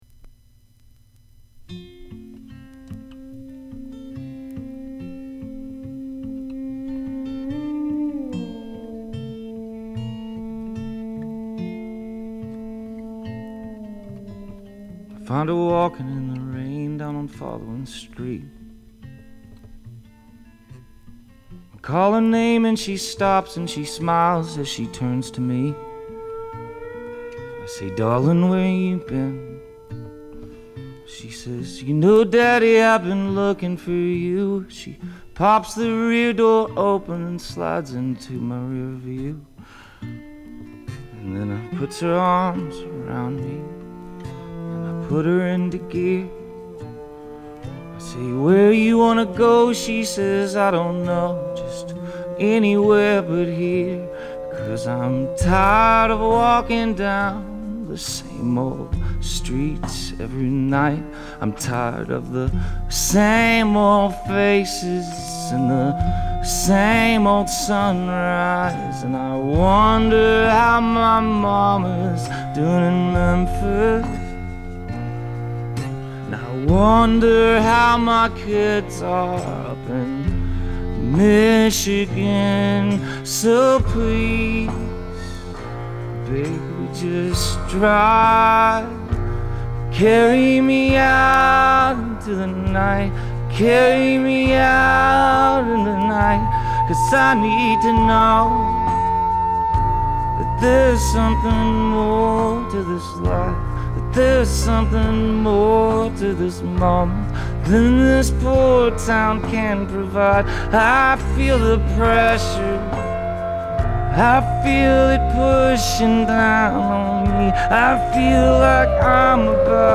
Best Album: Country Edition